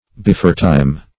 Beforetime \Be*fore"time`\, adv.